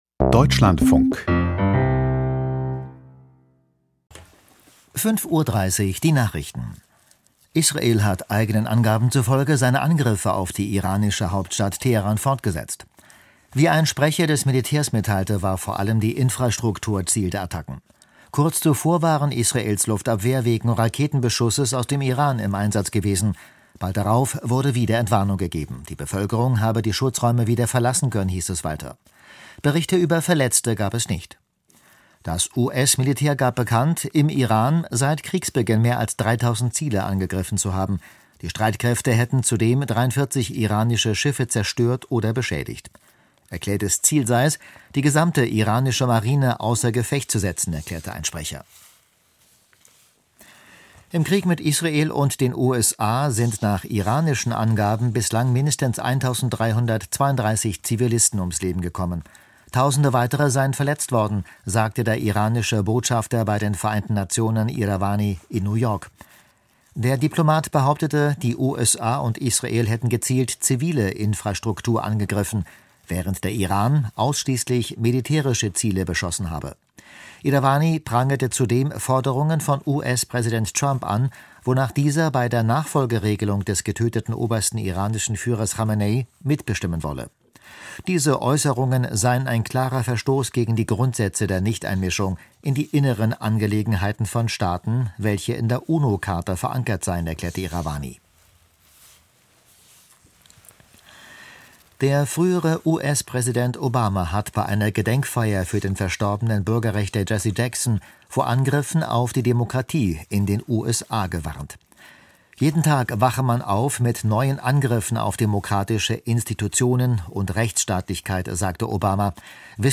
Die Nachrichten vom 07.03.2026, 05:30 Uhr